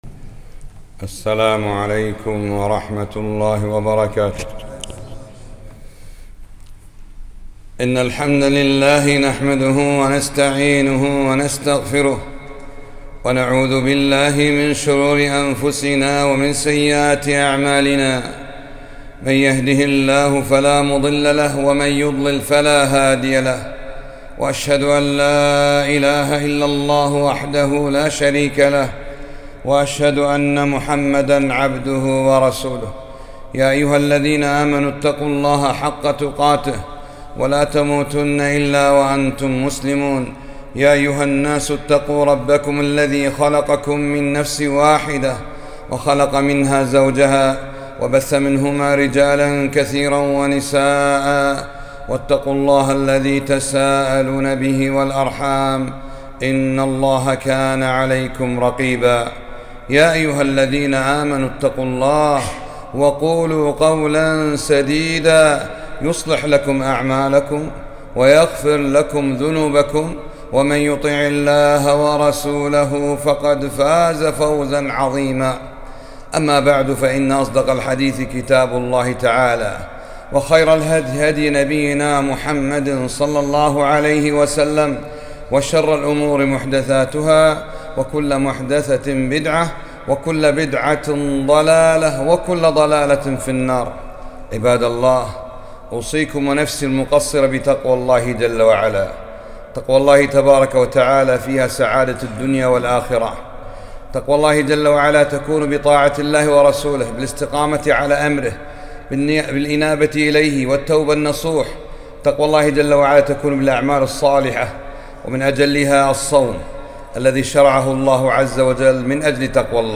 خطبة - من صام رمضان إيماناً واحتساباً